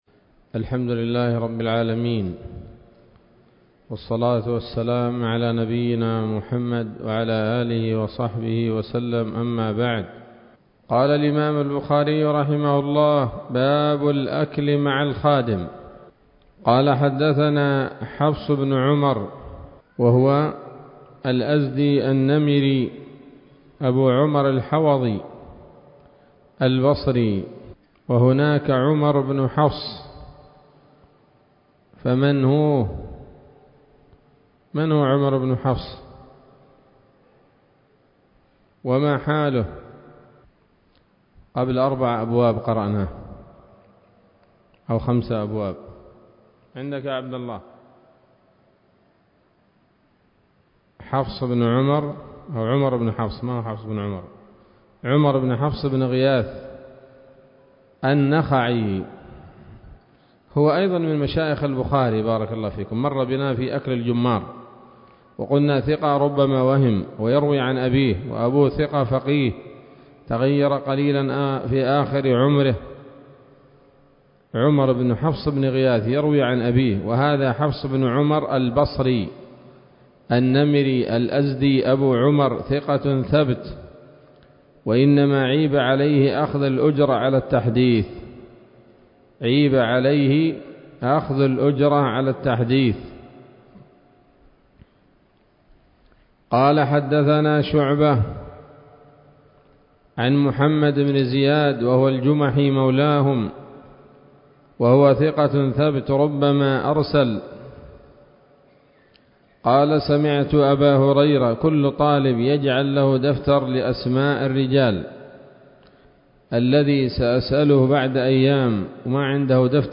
الدرس التاسع والعشرون من كتاب الأطعمة من صحيح الإمام البخاري